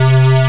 Index of /server/sound/weapons/tfa_cso/cyclone
fire.wav